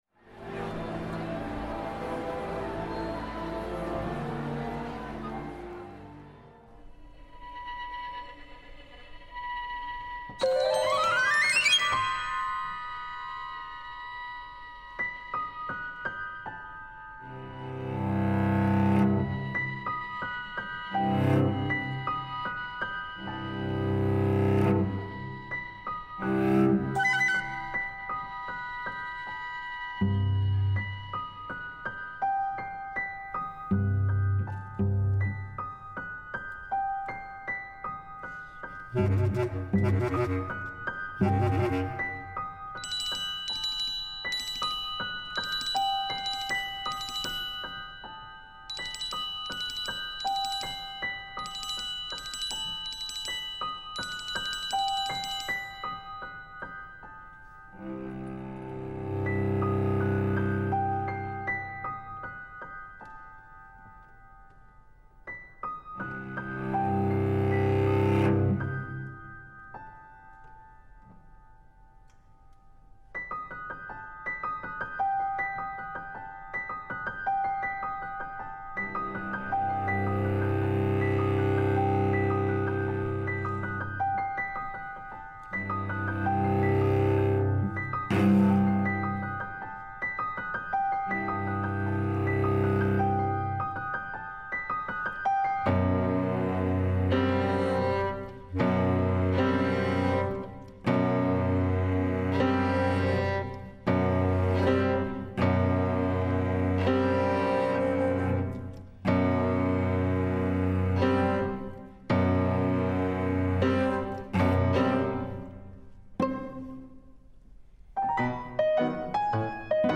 Access Contemporary Music’s popular Sound of Silent Film Festival celebrates twenty years of presenting modern silent films with newly commissioned scores performed live.